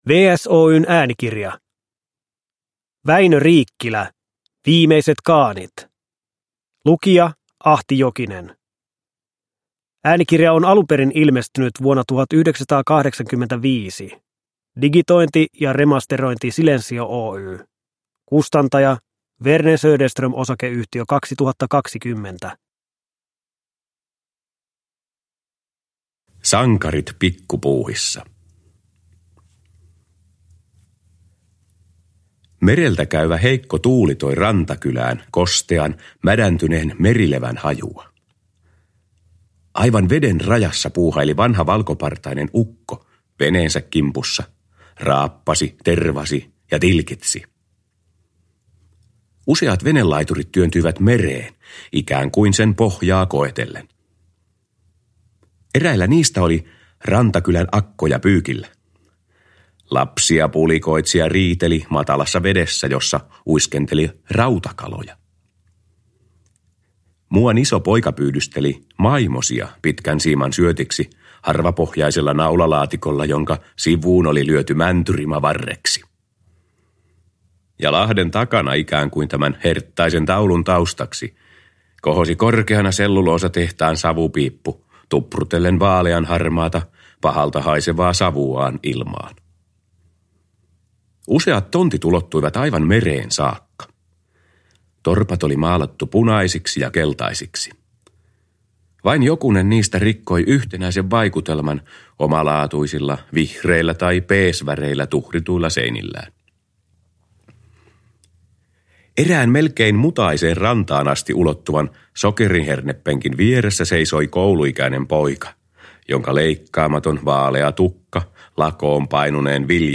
Pertsa ja Kilu: Viimeiset kaanit – Ljudbok – Laddas ner
Produkttyp: Digitala böcker